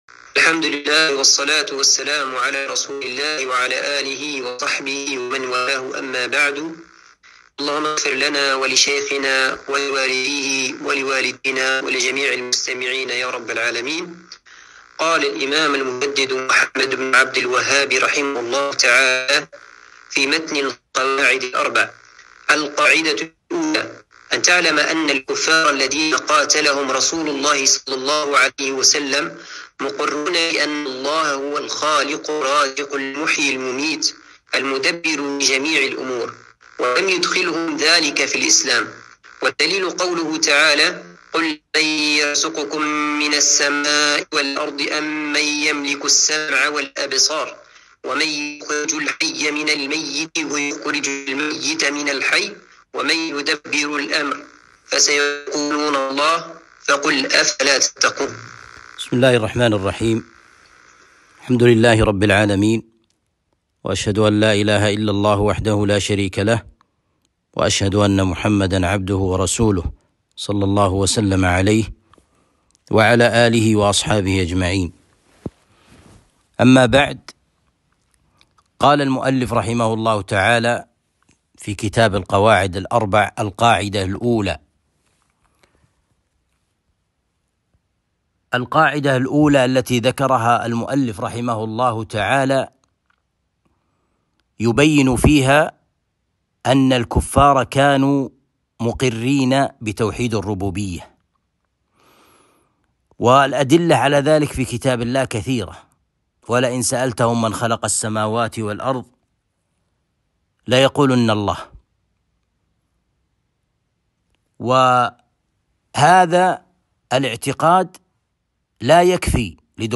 القواعد الأربع الدرس الثالث